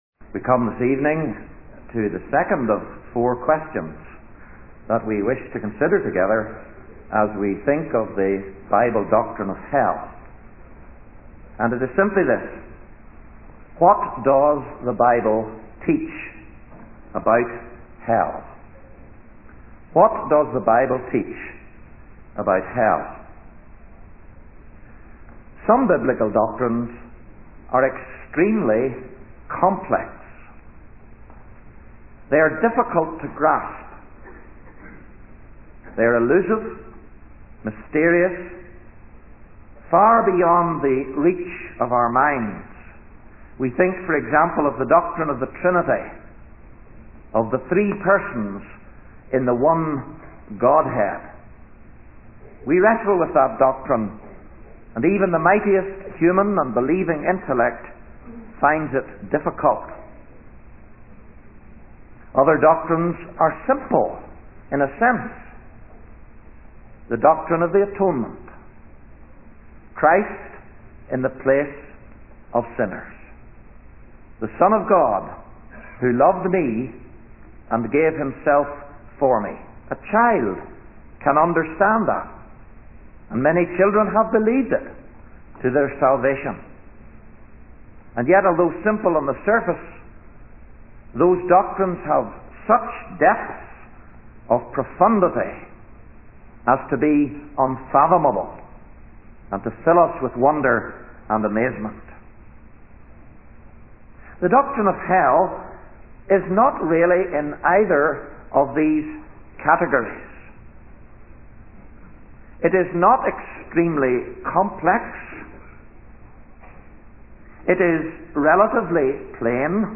In this sermon, the preacher addresses the disturbing and alarming statements found in the scripture.